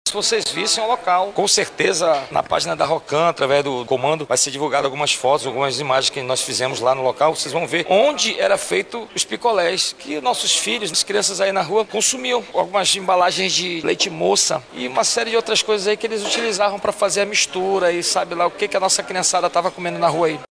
SONORA-1-PRESOS-FALSIFICACAO-BEBIDAS-.mp3